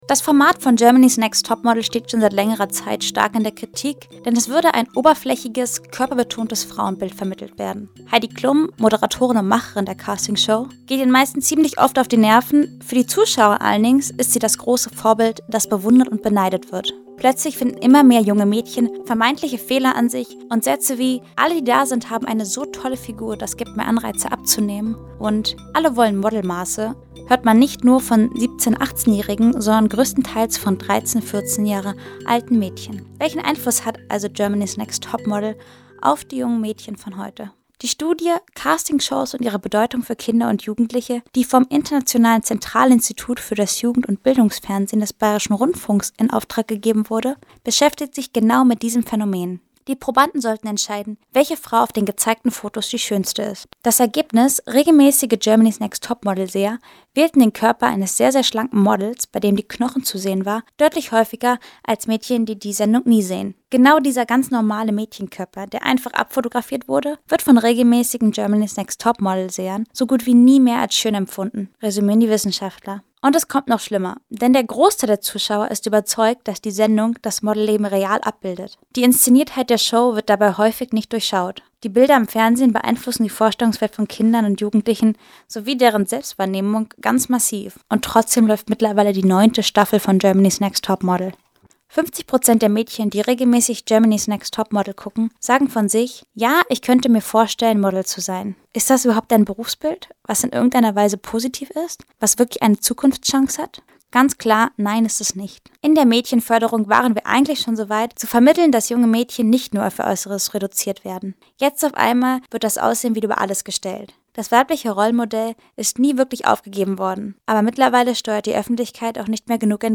Da kann schon einmal vergessen werden, dass diese harmlose Sendung tiefgreifenden Einfluss auf ihre jungen Zuschauerinnen hat. Ein Kommentar